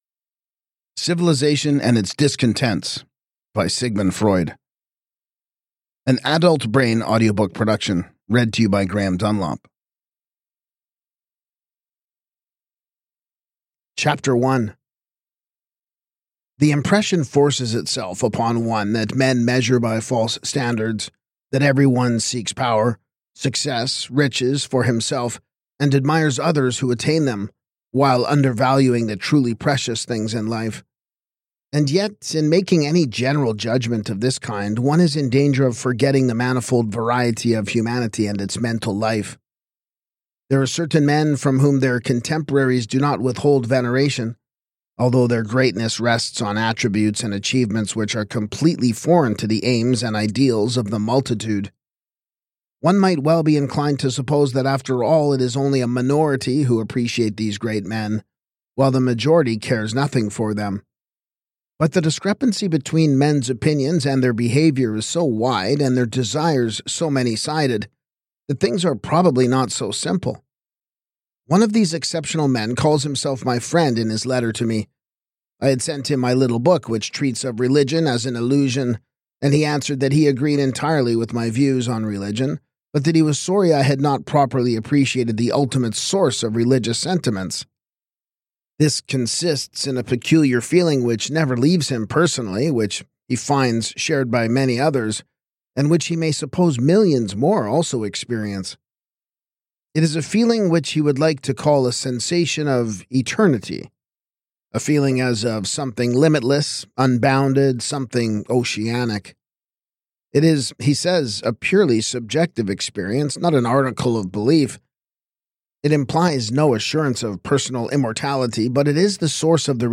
This audiobook brings Freud’s ideas to life with clarity and intensity, making a cornerstone of psychoanalytic thought accessible to contemporary listeners.